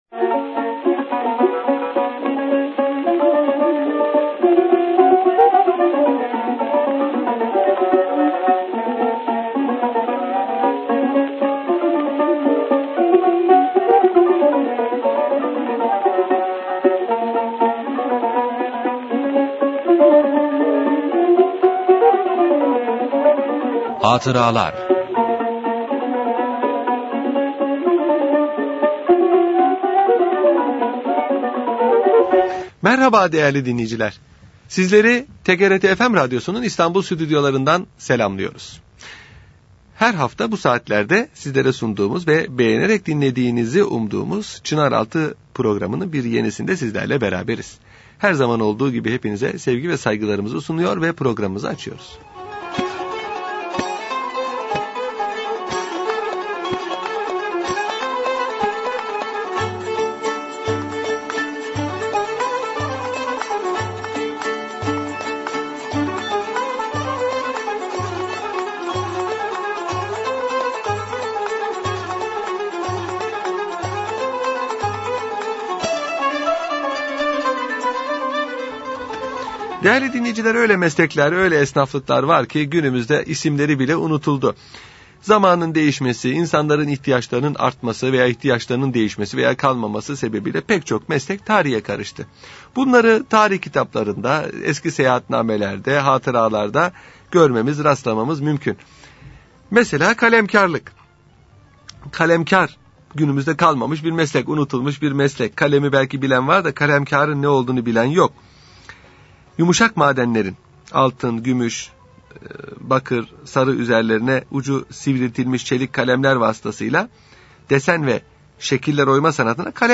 Radyo Programi - Kaybolan Meslekler 6